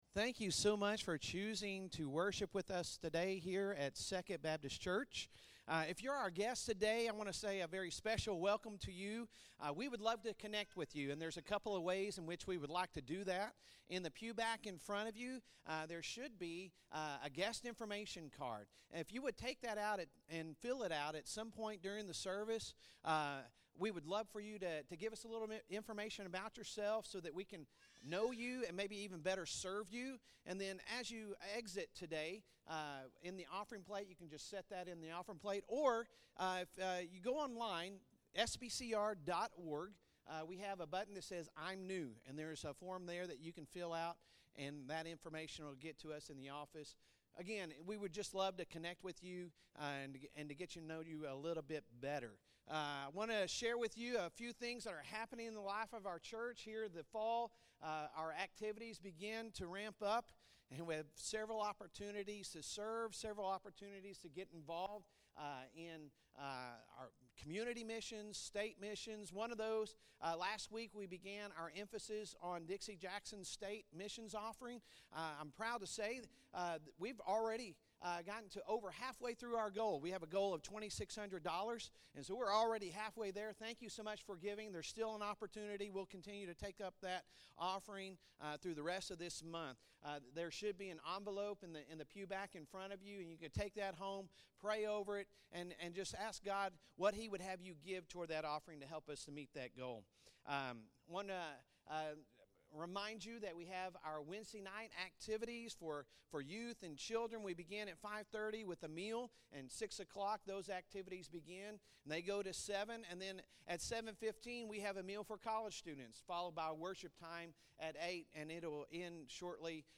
Sunday Sermon September 17, 2023